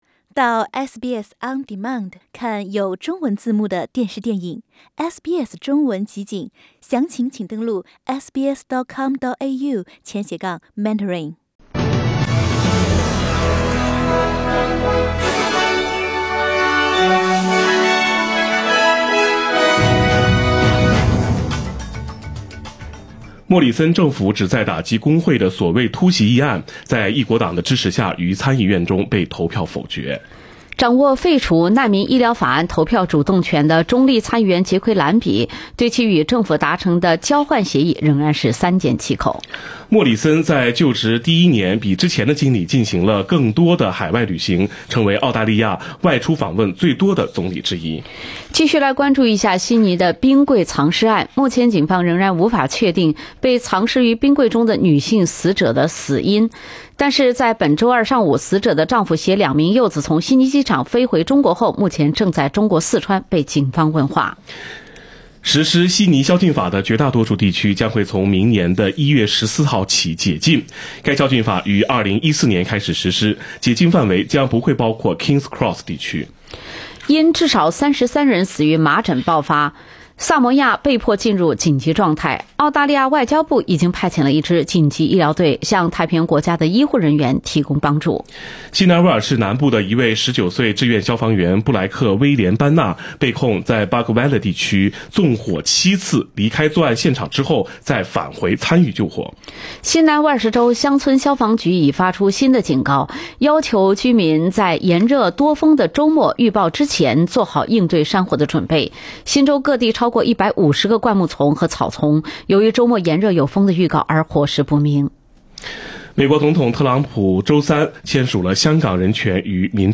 SBS 早新闻（11月29日）